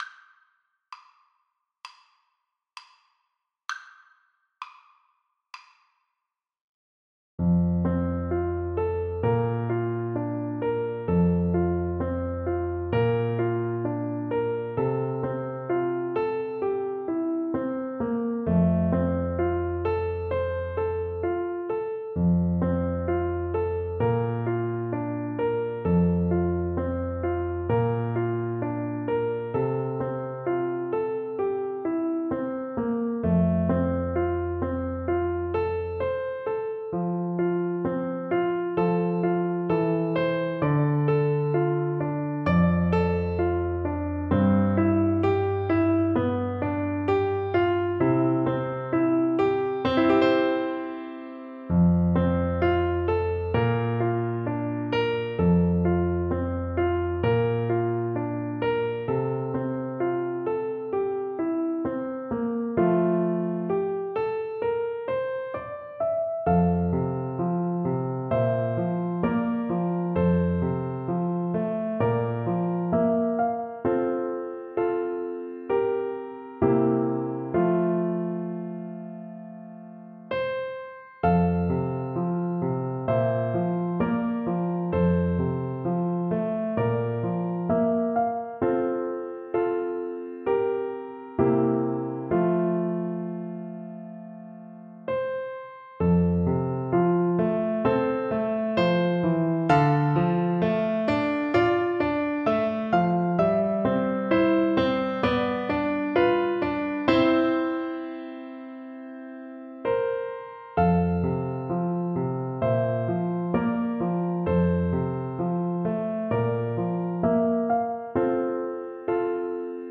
Clarinet version
Andante
4/4 (View more 4/4 Music)
Scottish